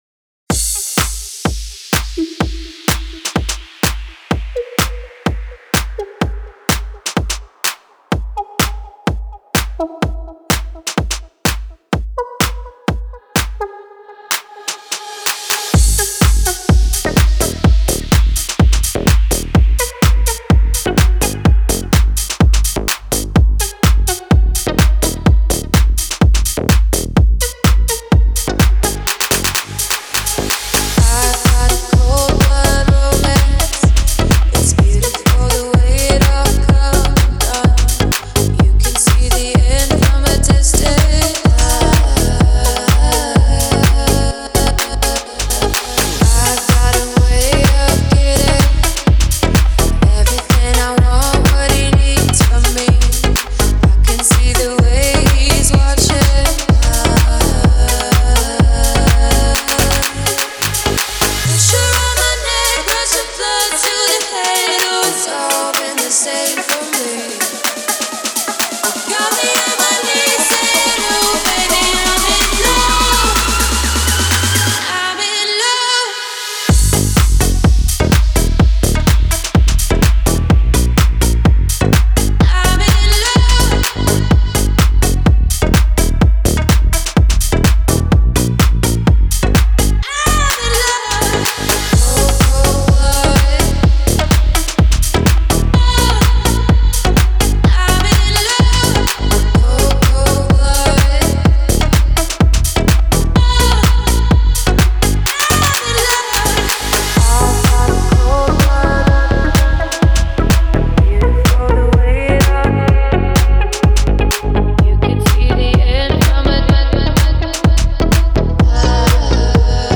это зажигательная композиция в жанре EDM